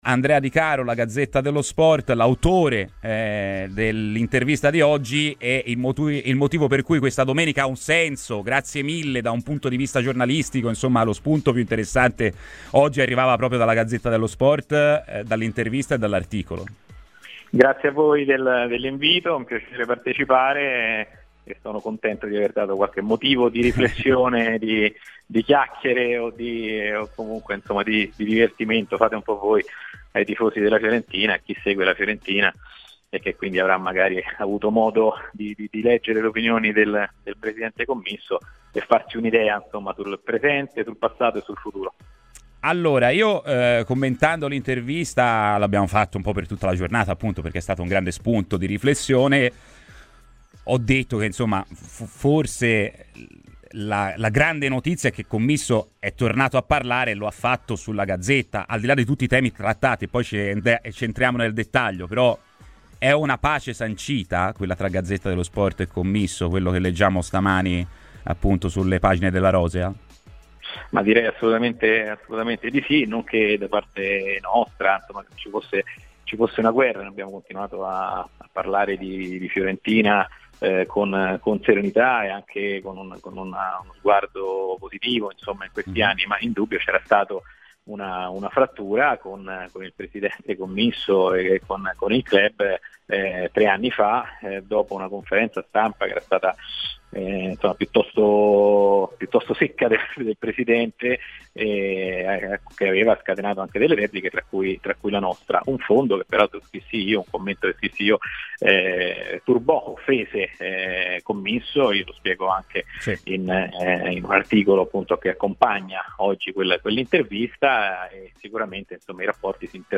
L'esclusiva
Radio FirenzeViola